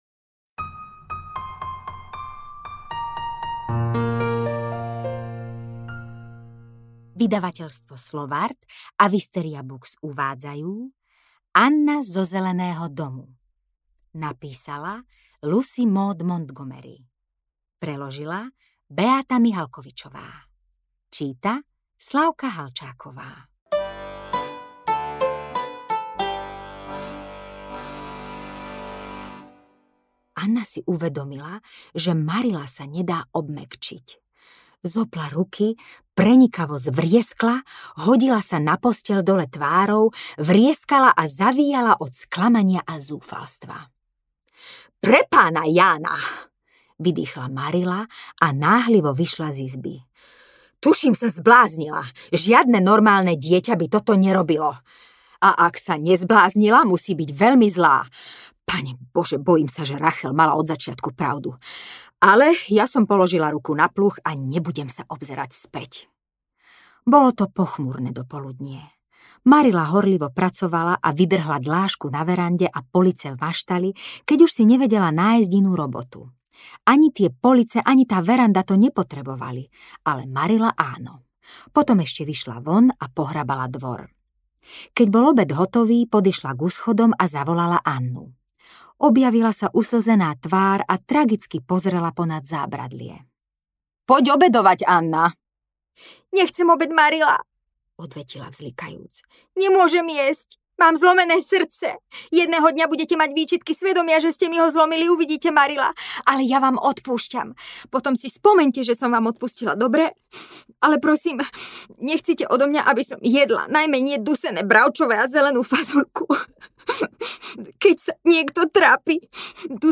Anna zo Zeleného domu audiokniha
Ukázka z knihy